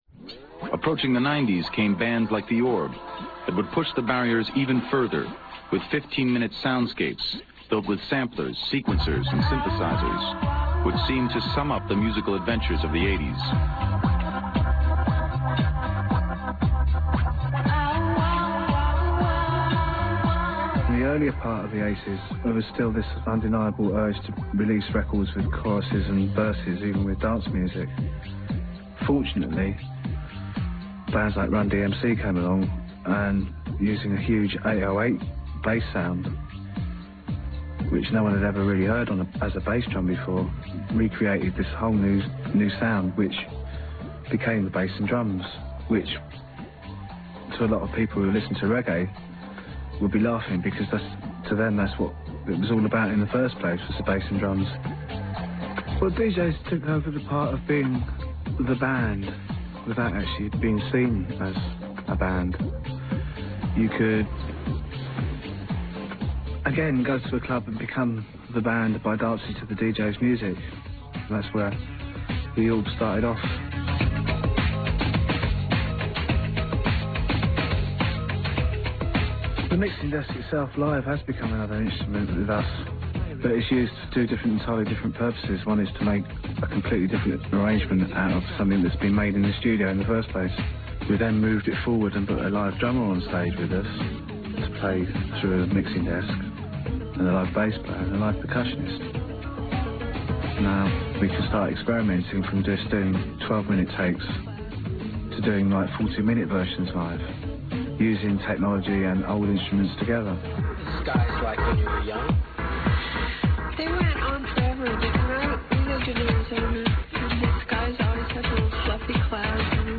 transcript of LX from the BBC documentary Dancing in the Street (picture &